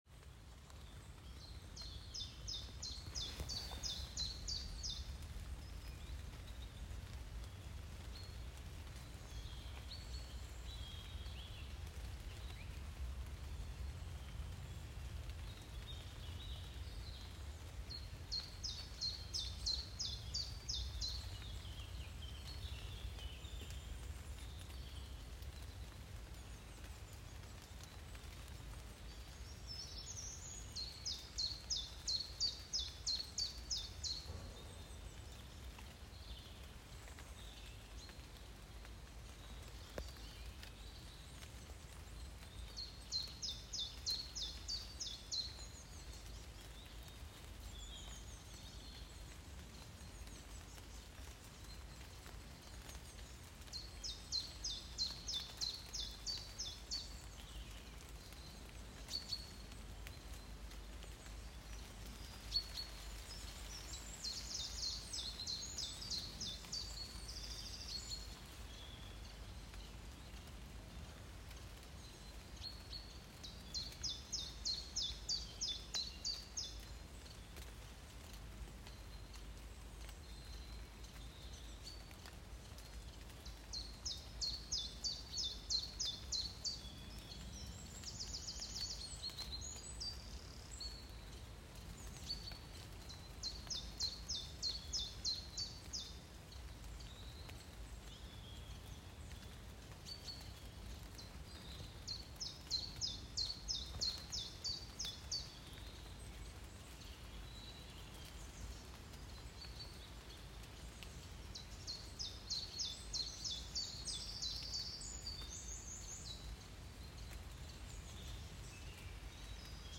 Ook door even stil te staan en te luisteren naar deze natuurlijke geluiden, kun je al een rustgevend signaal geven aan je lichaam.
Vogel.m4a